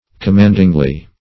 commandingly - definition of commandingly - synonyms, pronunciation, spelling from Free Dictionary Search Result for " commandingly" : The Collaborative International Dictionary of English v.0.48: Commandingly \Com*mand"ing*ly\, adv.